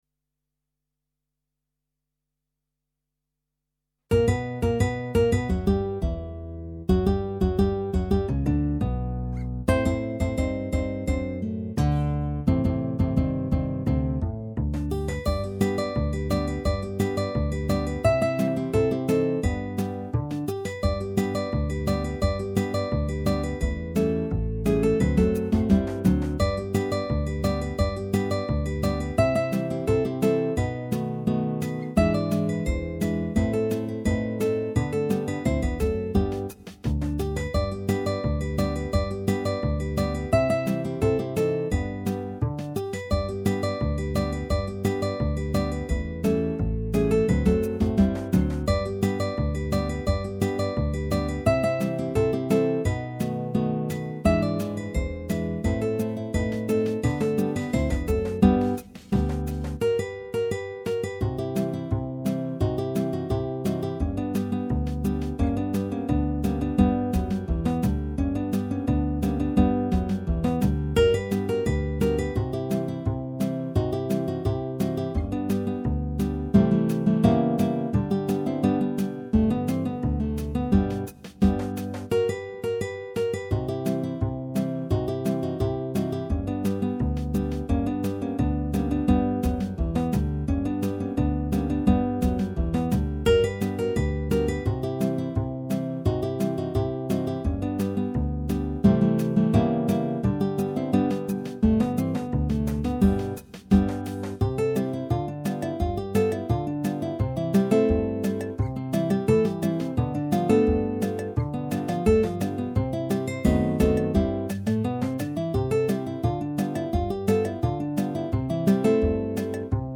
Guitar Quartet